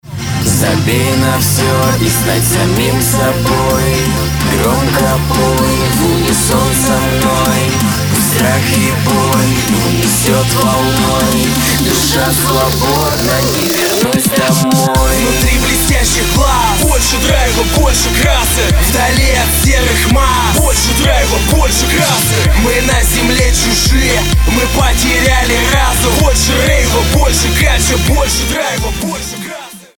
• Качество: 320, Stereo
поп
позитивные
мужской вокал
женский вокал
дуэт
добрые